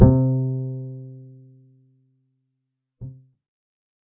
Bass Original and to see Neural networks output click this link
Bass-Original.mp3